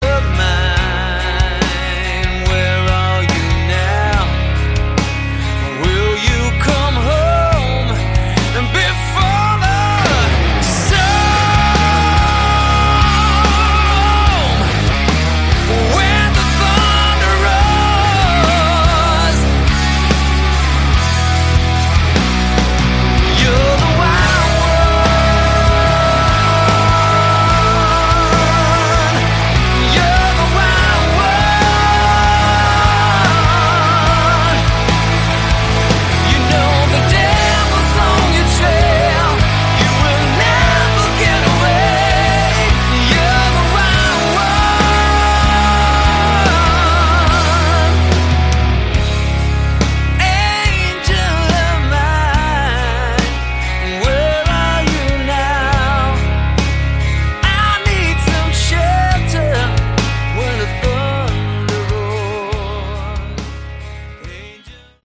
Category: Hard Rock
vocals, guitars
bass
drums, backing vocals